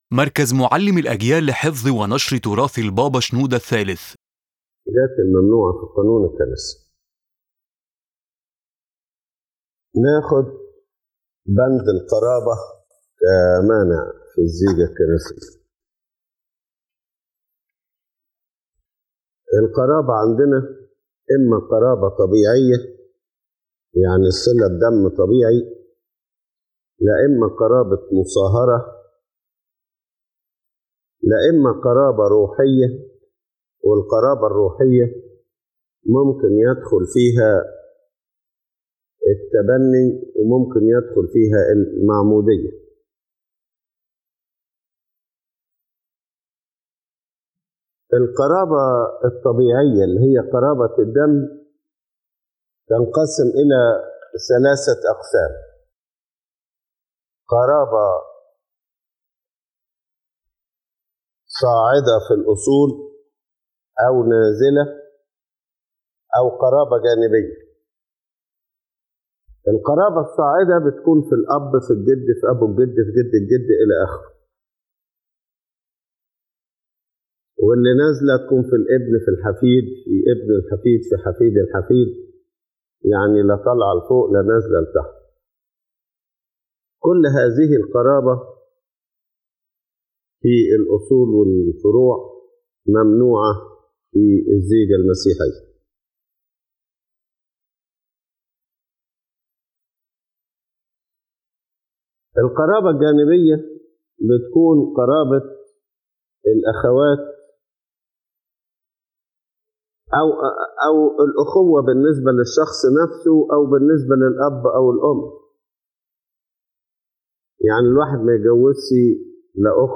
His Holiness Pope Shenouda III explains that kinship preventing marriage is divided into three categories: natural kinship (blood relations), affinity by marriage, and spiritual kinship, which includes adoption and baptism.